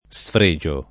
Sfr%Jo o Sfr$Jo], ‑gi — fut. sfregerò [SfreJer0+] — es.: non si sfregia Del pregio de la borsa e de la spada [non Si Sfr%Ja del pr$Jo de lla b1rSa e dde lla Sp#da] (Dante); Perché tuoi pregi Profani e sfregi? [perk% ttUqi pr$Ji prof#ni e Sfr%Ji?] (Cesarotti) — cfr. fregio